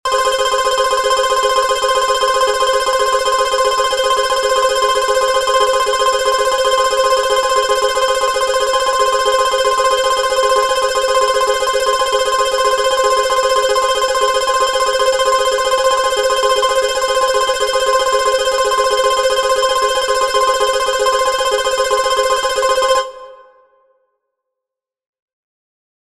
発車メロディー一覧
3番線 特急ホーム 桜乃･凛咲･直通(中山･鱒ノ宮･関門台･神京･姫川中央)(一部 臘花中央方面)
10発車ベル.mp3